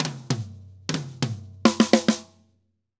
Drum_Break 100_4.wav